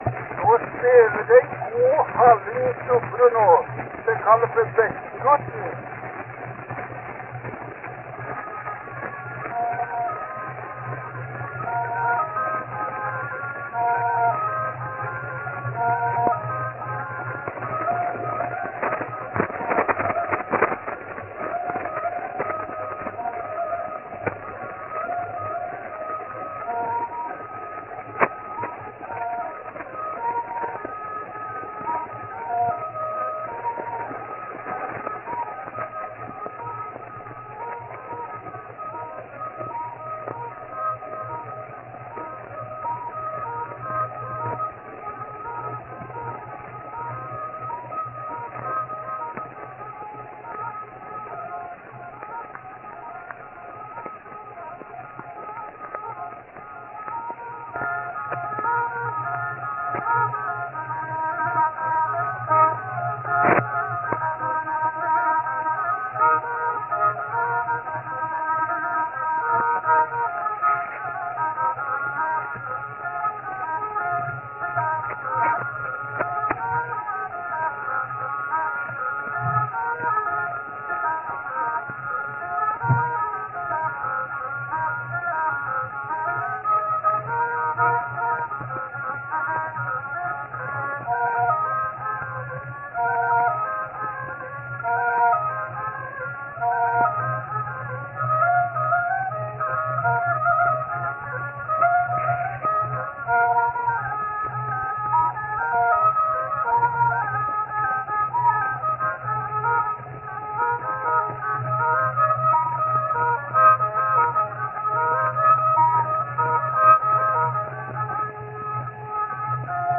Lytt til digitaliserte voksruller
Opptakene er også de eldste lydopptakene av hardingfelespill vi vet om i dag.